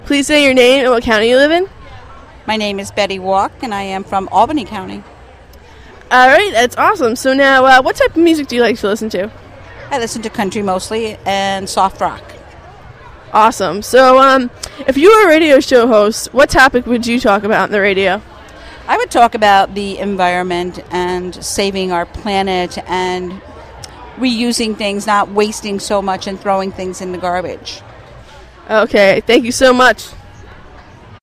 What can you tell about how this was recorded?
Greene County Youth Fair